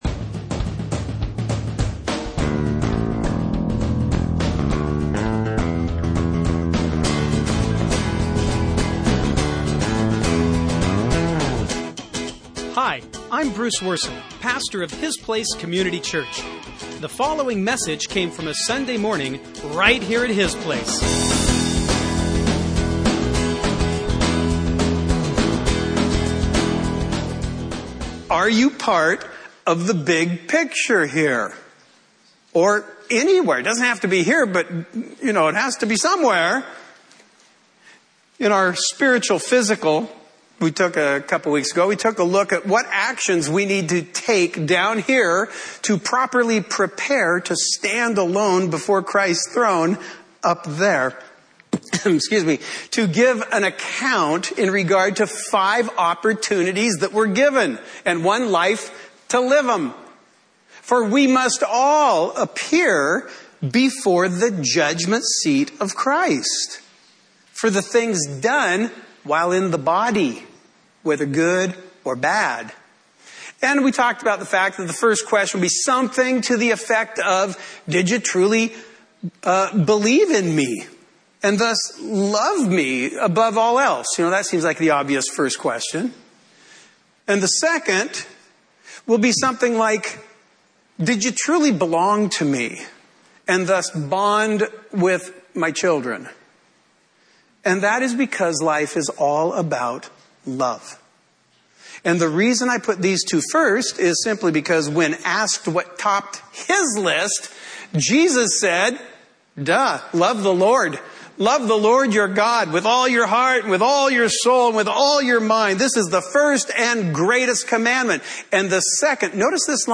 Sunday morning messages from His Place Community Church in Burlington, Washington. These surprisingly candid teachings incorporate a balanced mix of lighthearted self-awareness and thoughtful God-awareness.